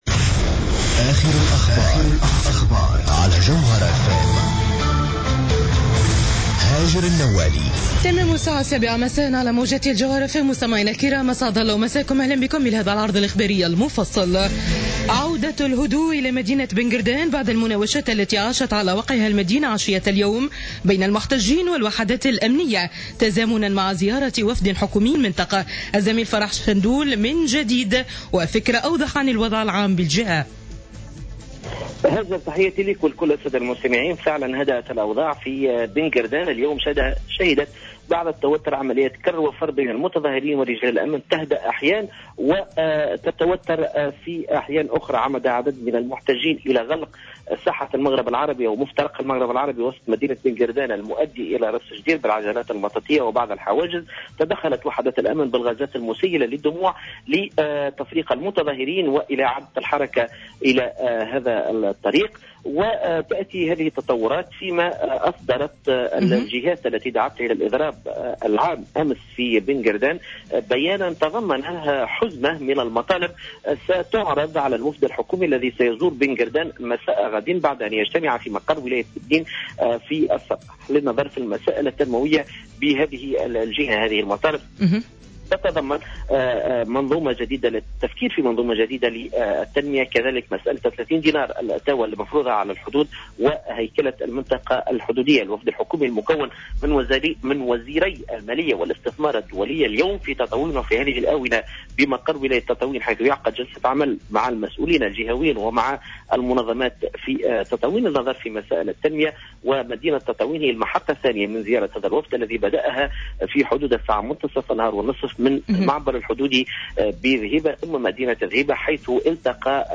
نشرة أخبار السابعة مساء ليوم الاربعاء 11 فيفري 2015